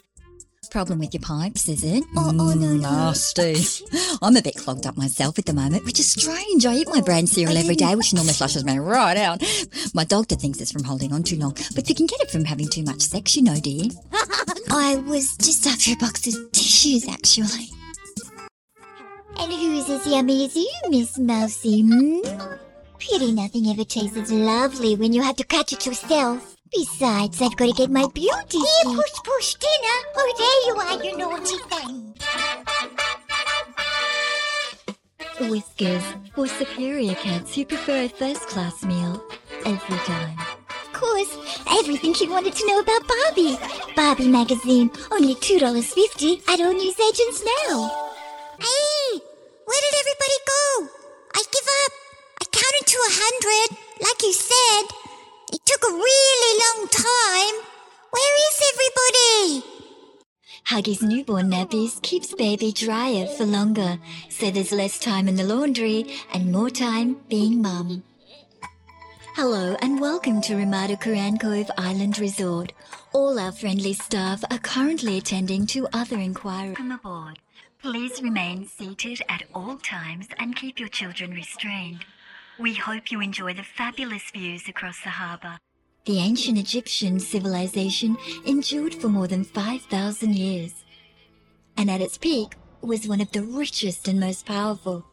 The Voice Realm is your leading directory for professional female voice talent.
A smooth, rich sound that can move from informative to playful. A low, smooth tone and clear RP speech that brings imagery to life.
English (British) Adult (30-50)